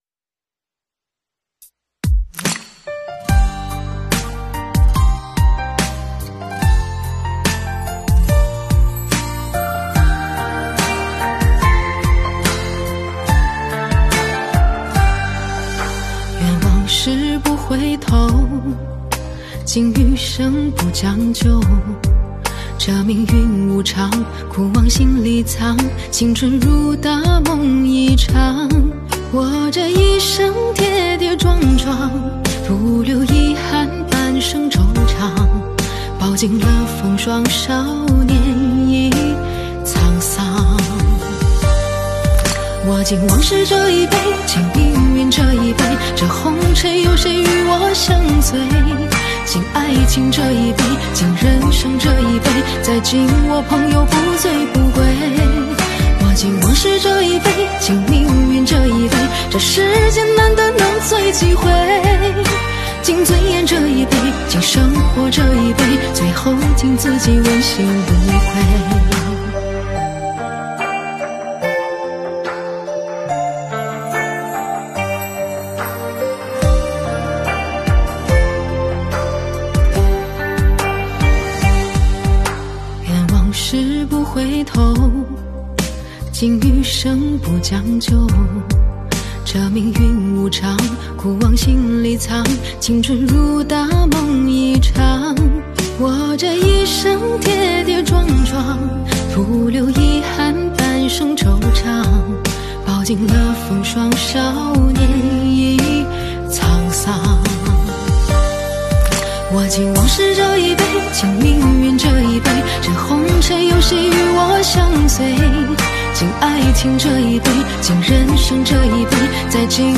经典流行